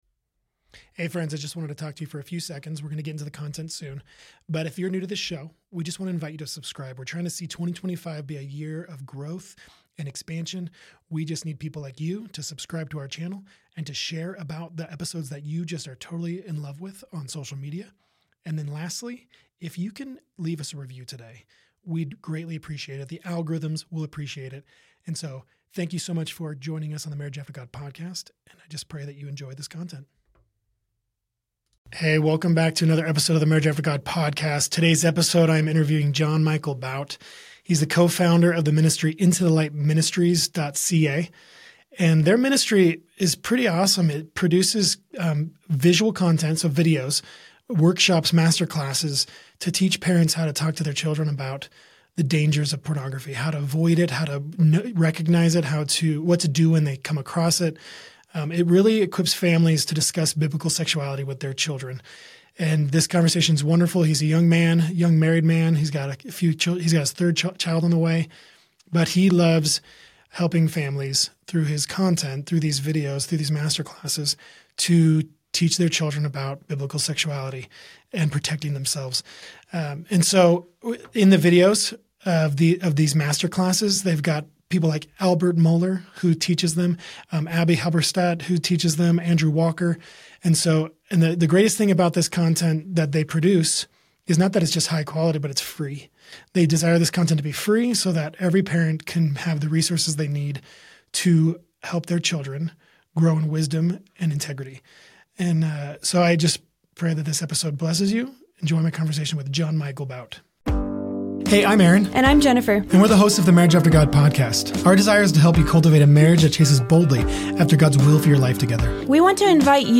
Finding Joy In Every Season - Interview